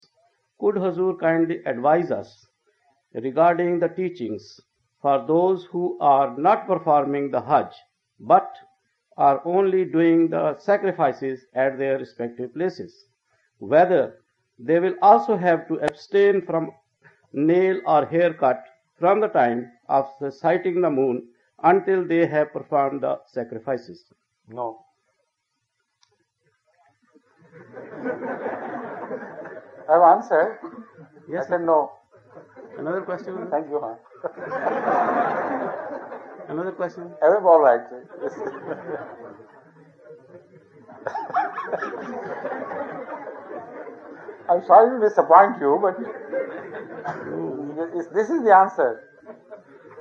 The London Mosque